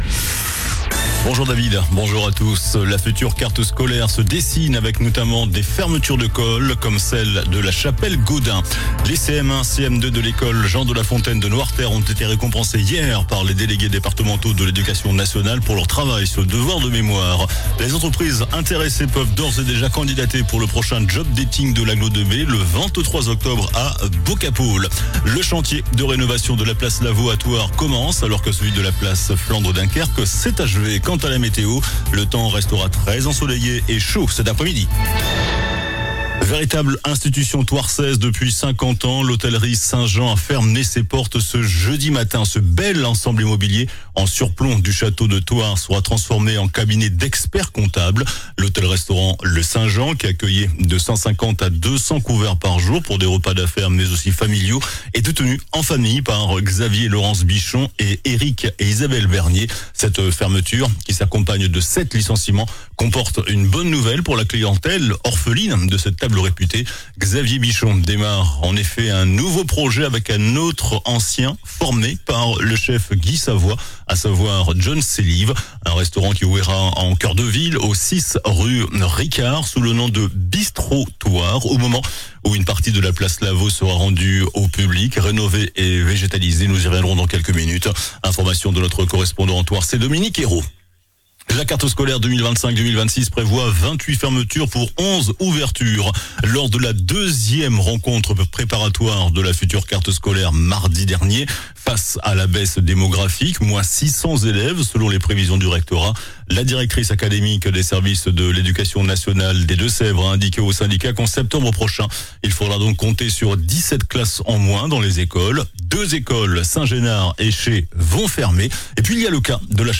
JOURNAL DU JEUDI 19 JUIN ( MIDI )